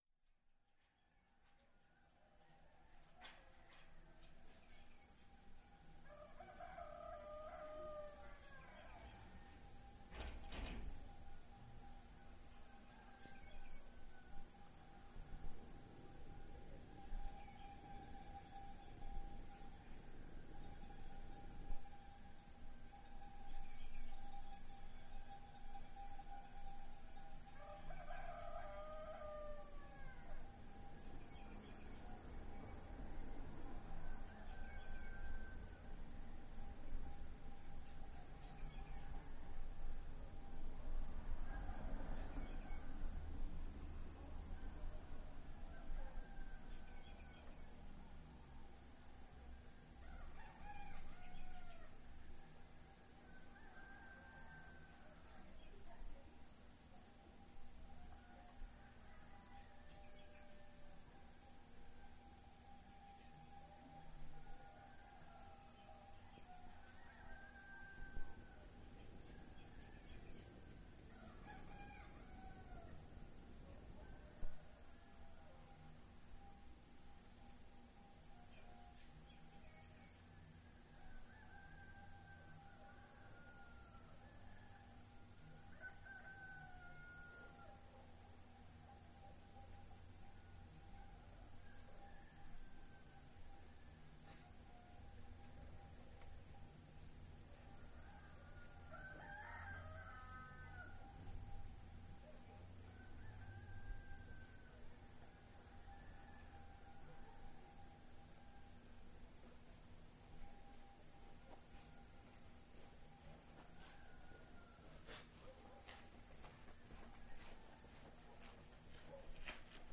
Amanecer en Suchiapa, Chis. México
Gallos, pájaros, Cuijas (Gekos), perros ladrando, una persona caminando a la terminal de autobuses para dirigírse a su trabajo en Tuxtla Gutierrez es lo que se escucha al amanecer,.
El día lunes y jueves pasa un señor con una campana anunciando que dentro de algunos minutos pasará el camión de la basura, para levantarnos e ir a depositar los botes en la esquina.